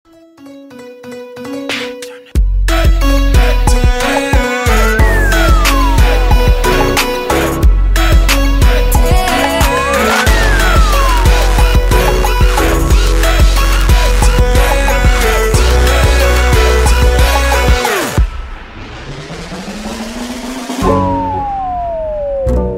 Android, Elektronisk musik, Trap & Bass